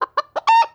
chicken-heck-sound